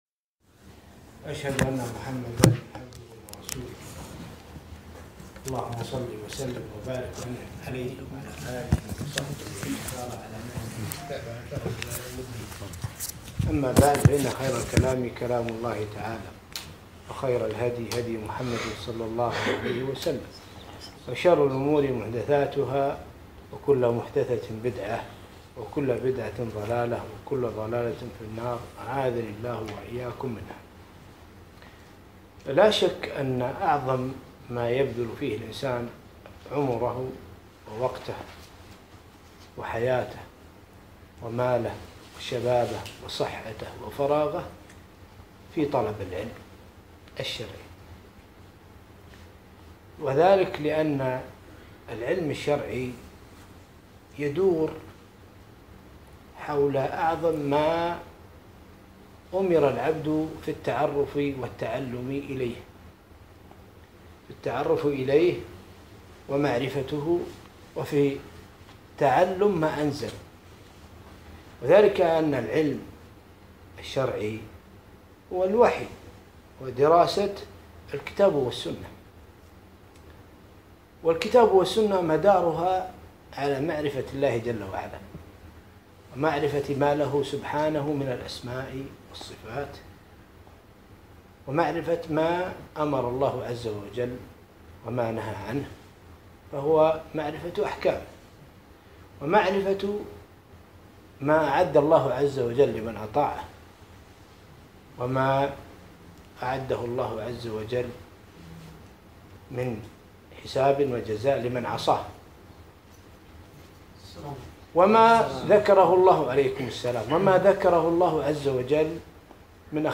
محاضرة - آداب طلب العلم